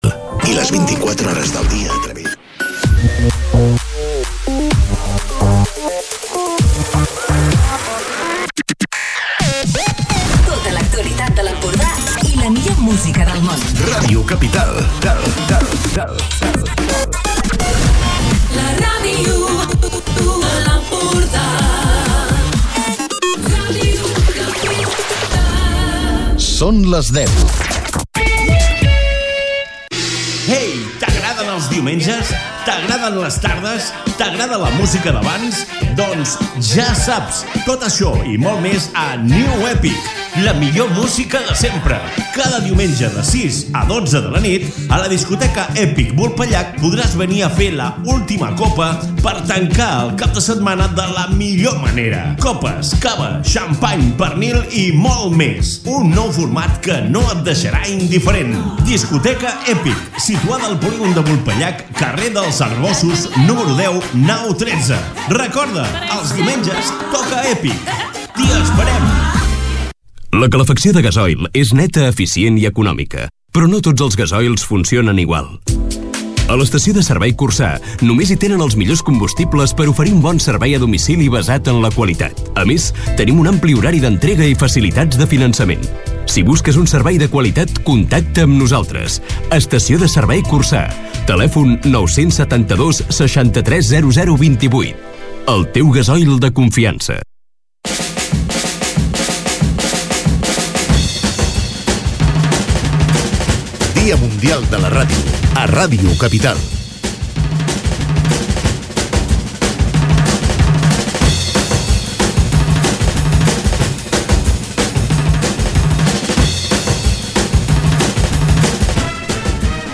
Durant més de quatre hores, per l’estudi de Ràdio Capital hi han passat persones i entitats de tot tipus, de tota la comarca del Baix Empordà. Hem tingut temps per parlar de música, de meteorologia, del trinomi joves, ràdio i xarxes socials i hem fins i tot hem recreat un fragment d’un capítol de la mítica sèrie Radio Cincinatti